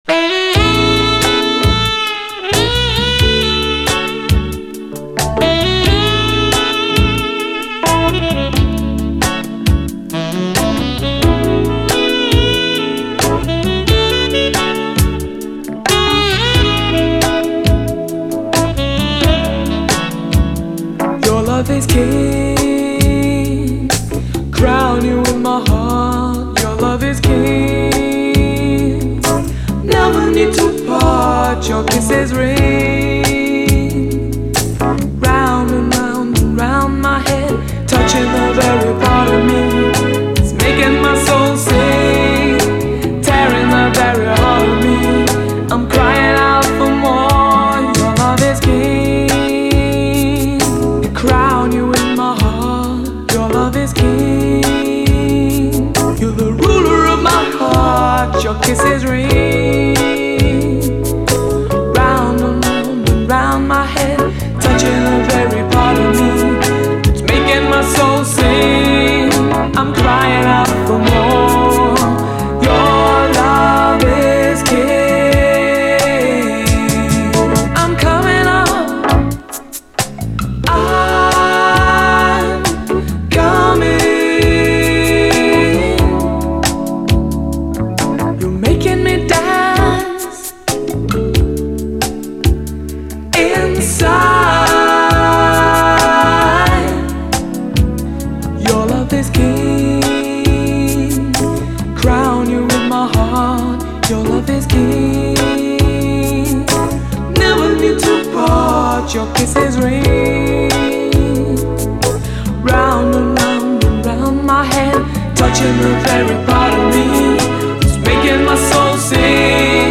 全て彼自身の手で録音されたエレガントな楽曲、美しいコーラス